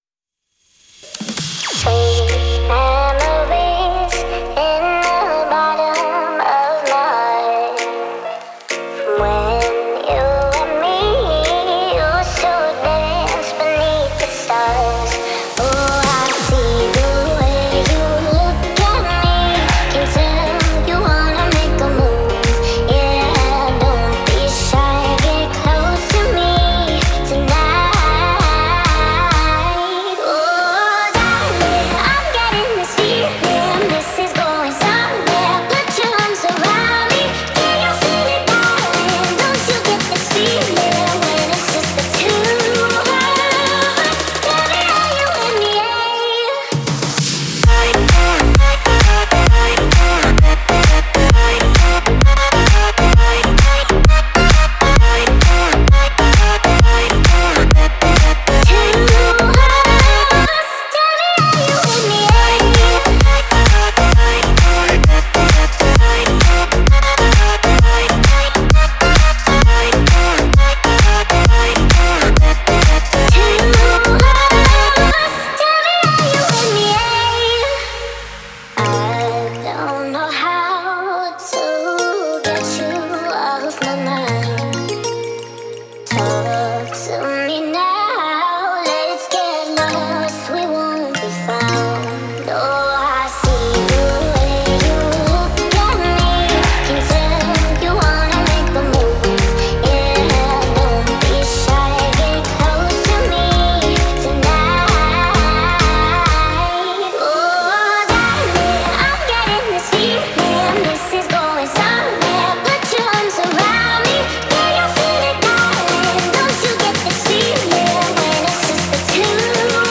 это нежная и мелодичная композиция в жанре поп